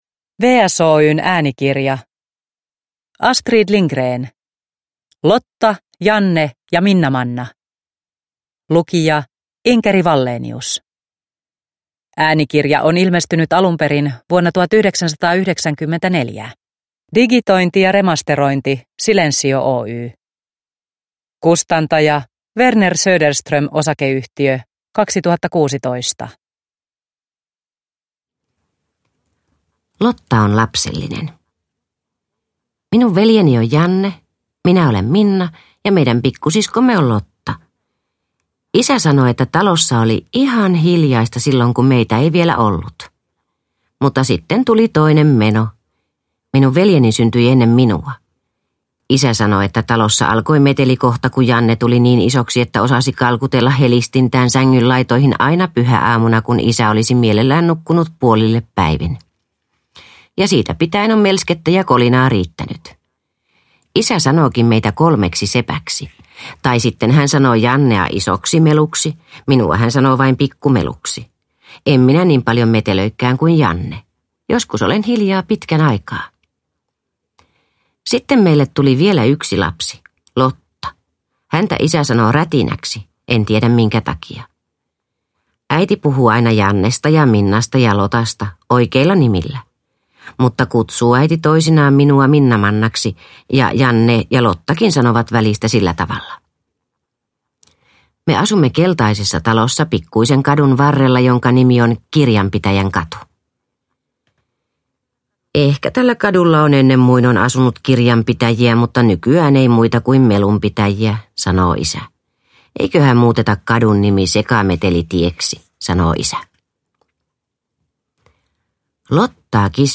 Lotta, Janne ja Minnamanna – Ljudbok – Laddas ner